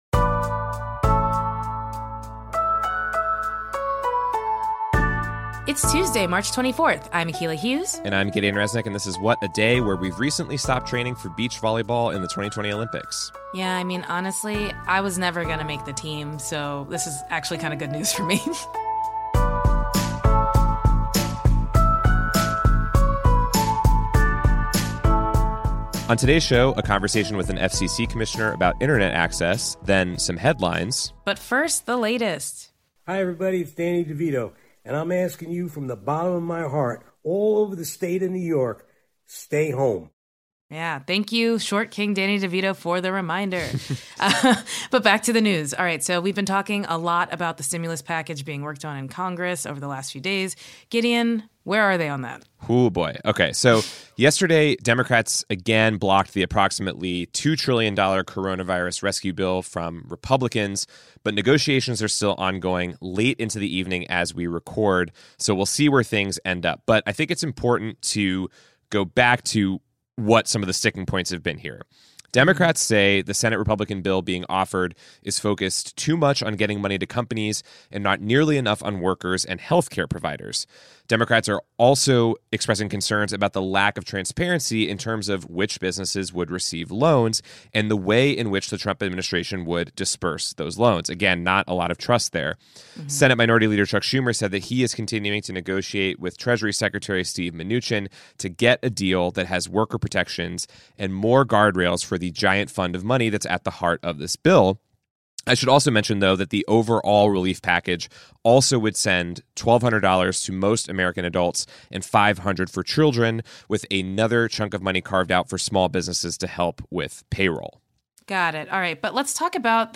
Is America’s internet prepared for all of us to be online all of the time? We interview FCC commissioner Jessica Rosenworcel to get her answer. And we discuss how to close digital divide to make sure all students can continue their education online through the pandemic.